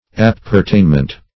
Search Result for " appertainment" : The Collaborative International Dictionary of English v.0.48: Appertainment \Ap`per*tain"ment\, n. That which appertains to a person; an appurtenance.